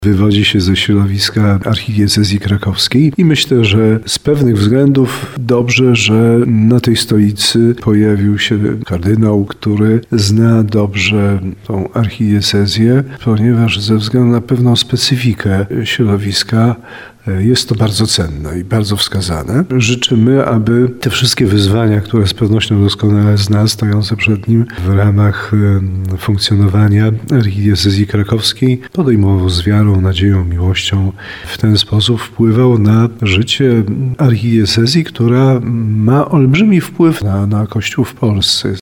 Zna dobrze archidiecezję krakowską, wie jakie są wyzwania – tak o nowym metropolicie krakowskim kardynale Grzegorzu Rysiu mówi biskup tarnowski Andrzej Jeż.